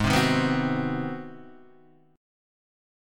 Ab7#9 chord